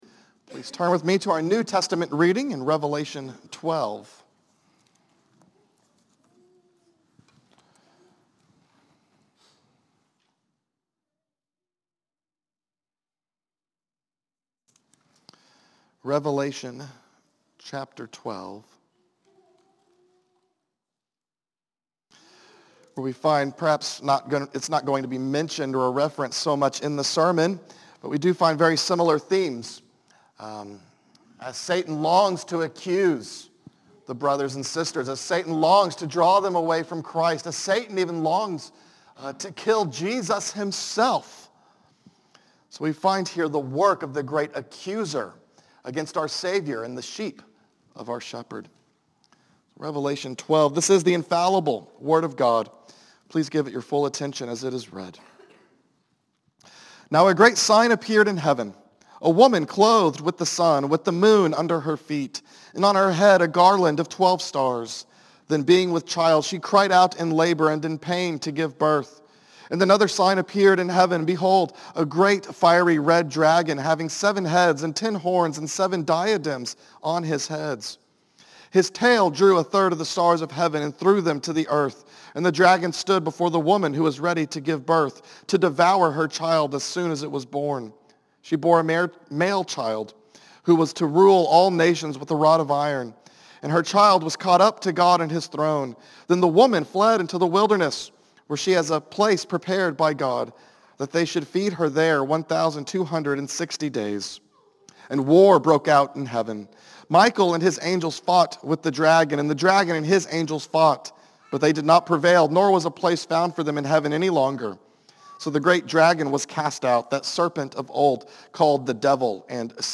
A message from the series "Zechariah."